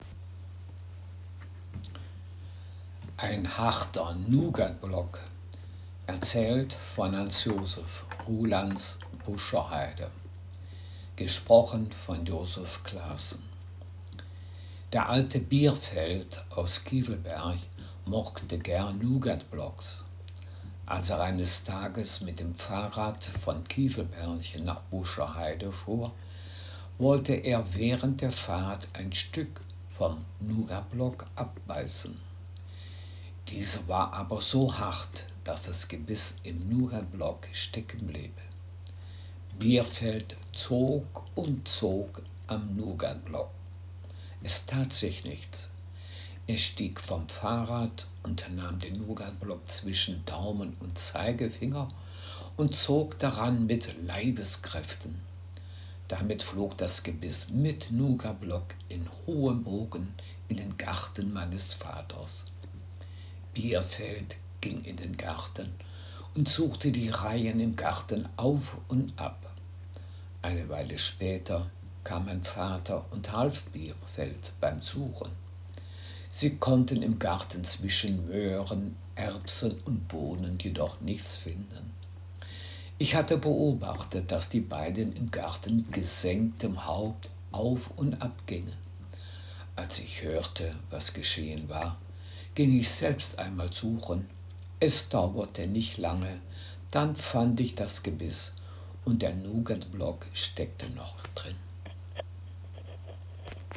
Geschichte